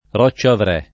[ r q©© avr $+ ]